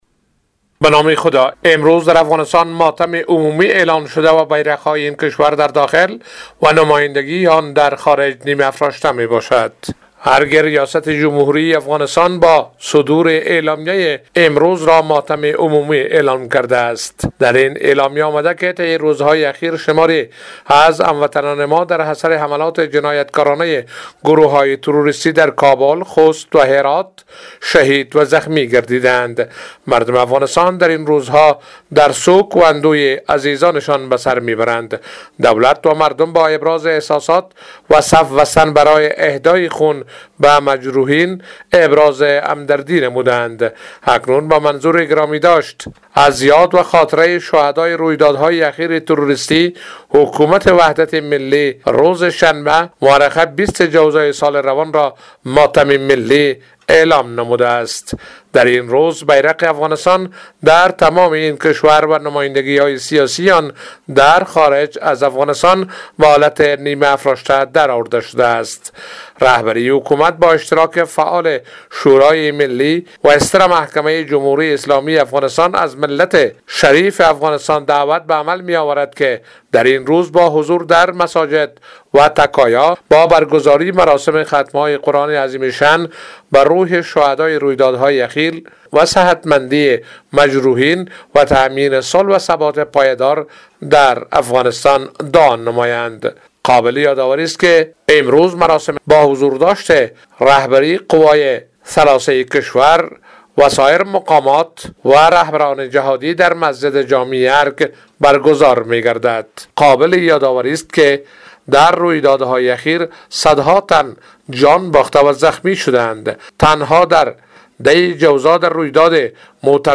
به گزارش خبرنگار رادیو دری،، در بیانیه ریاست جمهوری افغانستان آمده است، در این روز پرچم این کشور در داخل و تمامی نمایندگی های سیاسی خارج از افغانستان به حالت نیمه افراشته درخواهد آمد.